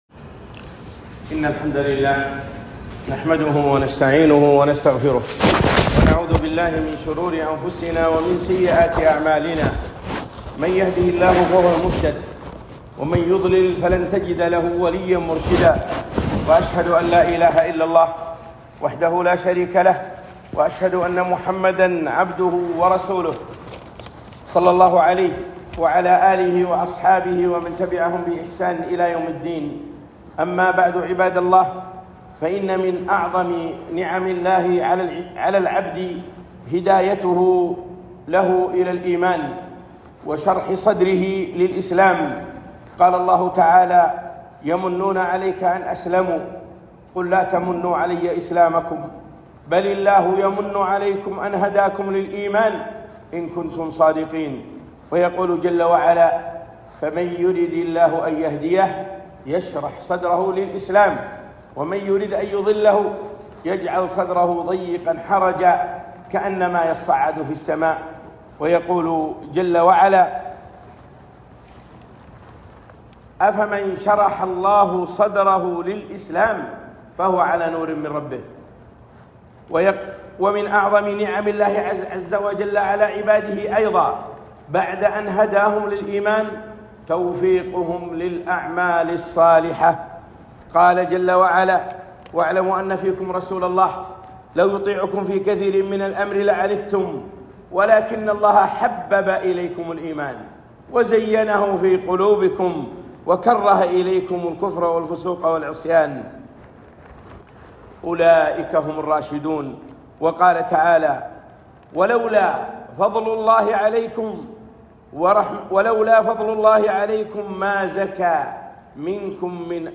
خطبة - التحذير من مبطلات العمل الصالح
ألقيت يوم الجمعة ٢٢ ذي الحجة ١٤٤٥ في المدينة النبوية